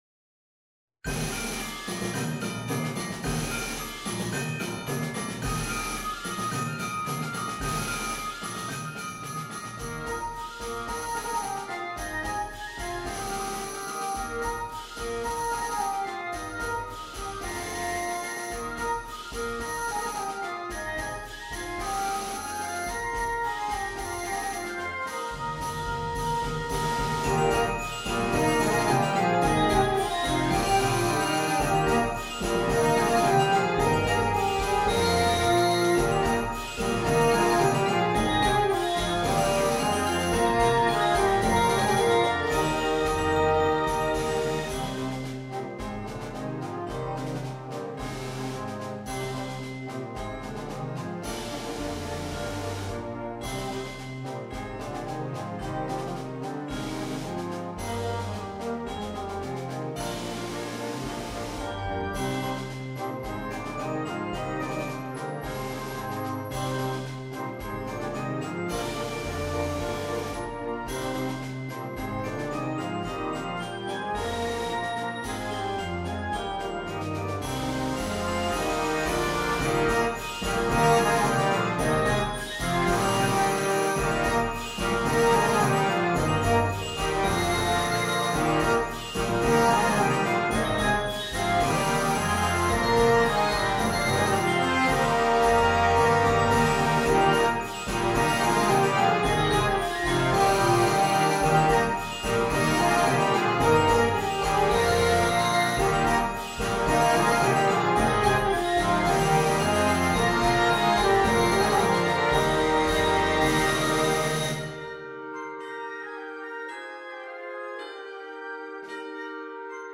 is a brisk, uplifting piece of music
Very little time to rest and no slower middle section.